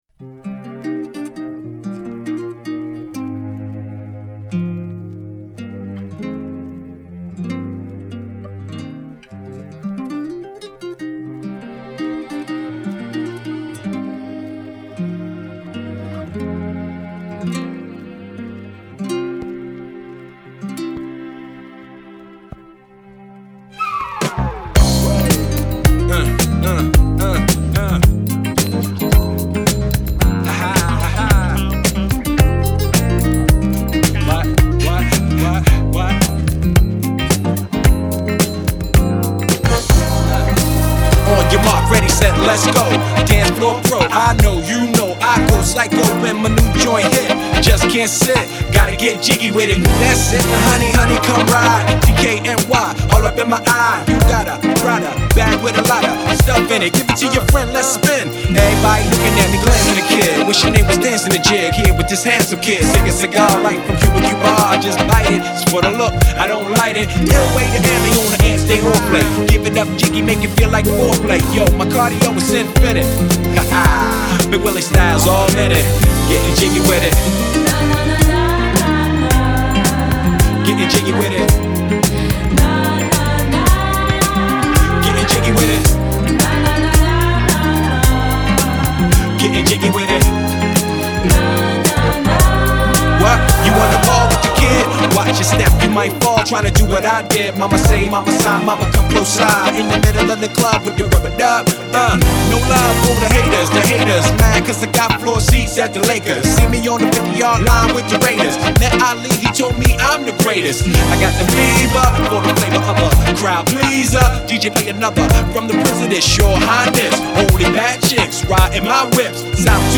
smooth sounds